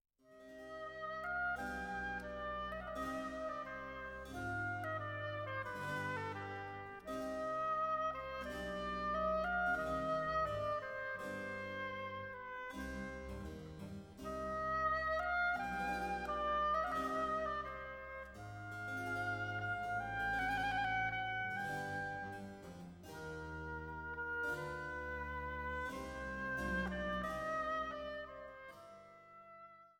für Trompete, Oboe und Basso continuo